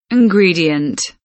ingredient kelimesinin anlamı, resimli anlatımı ve sesli okunuşu